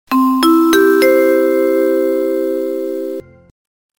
Genre: Efek suara